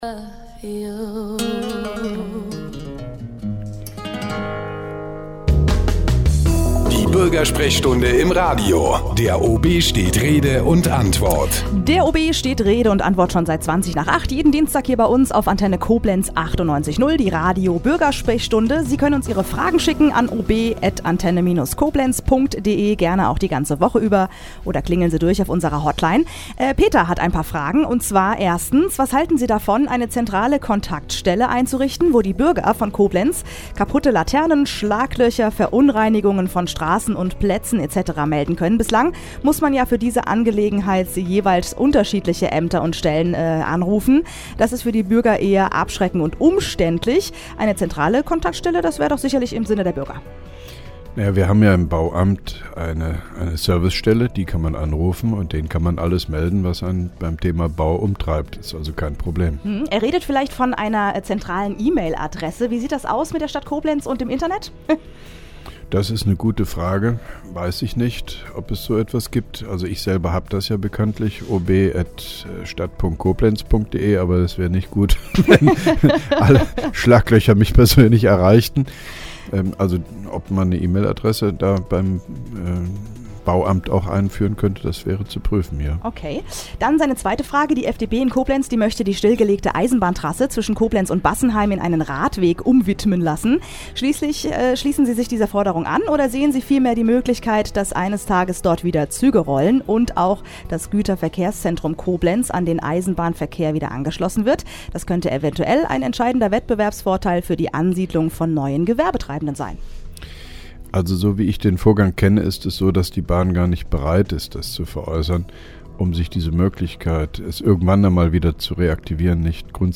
(3) Koblenzer OB Radio-Bürgersprechstunde 14.09.2010